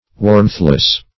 warmthless - definition of warmthless - synonyms, pronunciation, spelling from Free Dictionary
Search Result for " warmthless" : The Collaborative International Dictionary of English v.0.48: Warmthless \Warmth"less\, a. Being without warmth; not communicating warmth; cold.